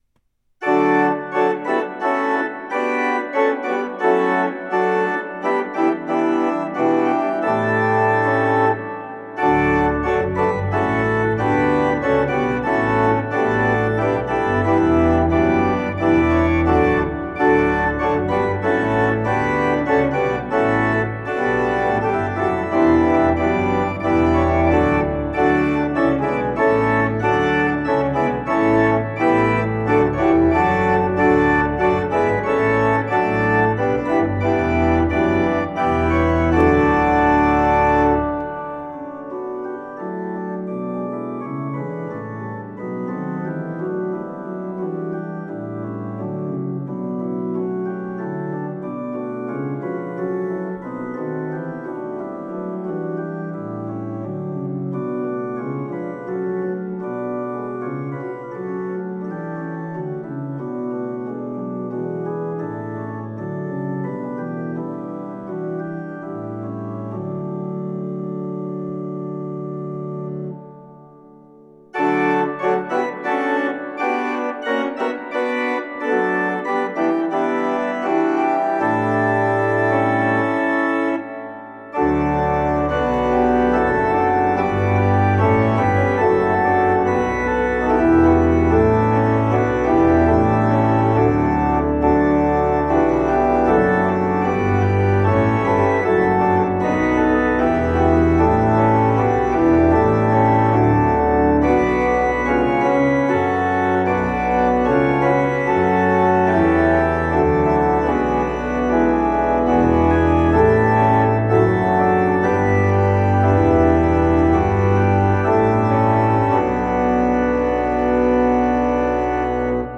It’s one of my favorite hymns, both because of its beauty and because it’s challenging to play on the organ.
Here I’ve taken the melody and put it in the pedals for the first verse.  The second verse is contrasting, with the pedals not used at all.  The final verse puts the pedals in their normal role of providing the bass.